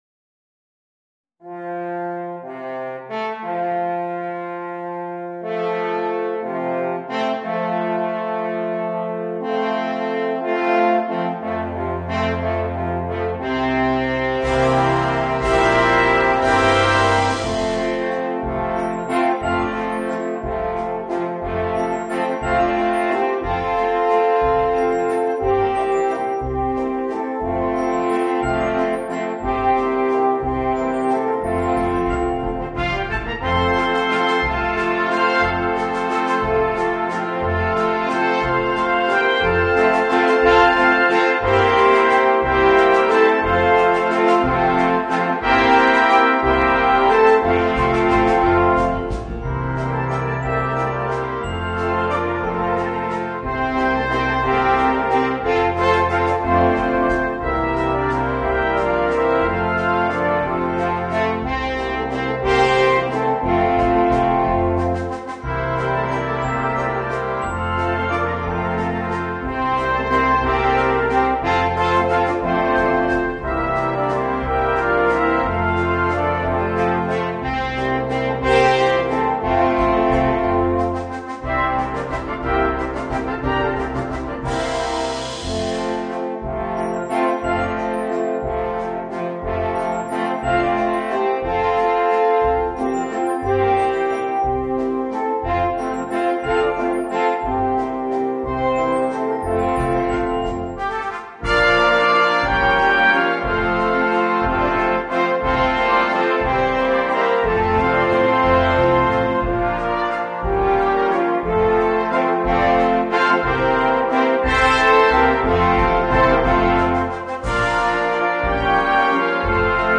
Voicing: Alphorn and Brass Band